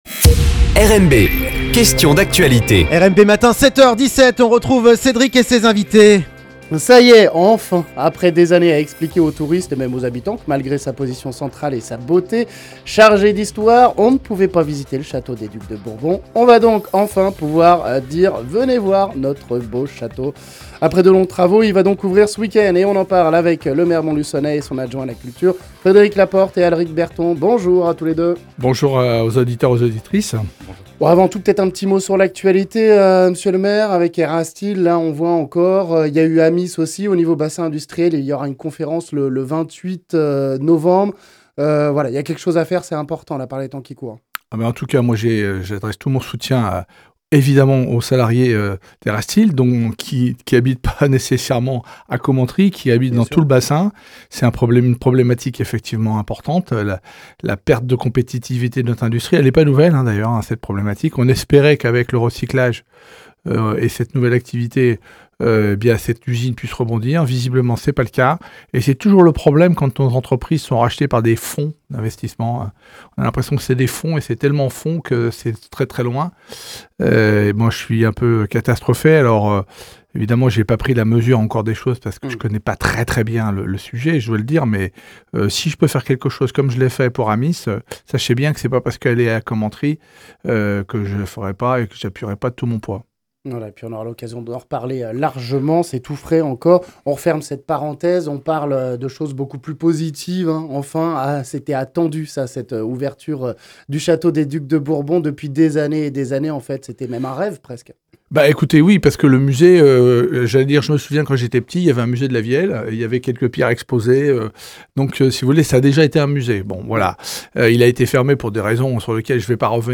On en parle ici avec le maire Frédéric Laporte et son adjoint à la culture Alric Berton.